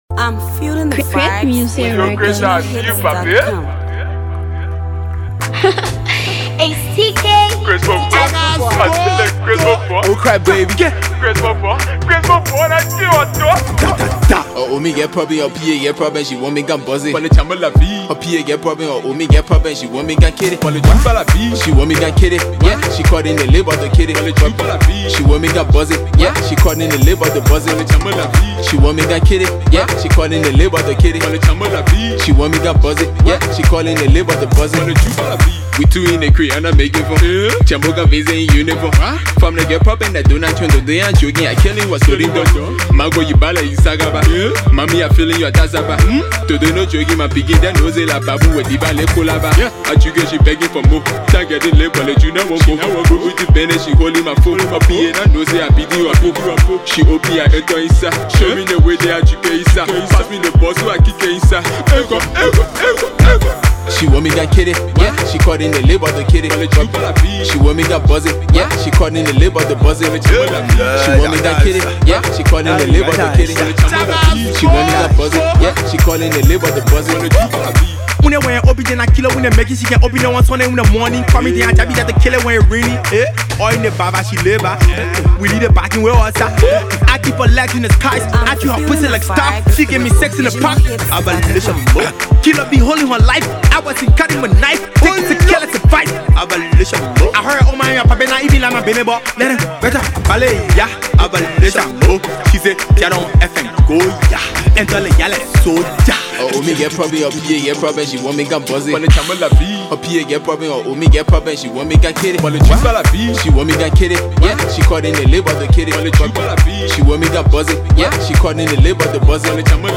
hot banger
Afro Pop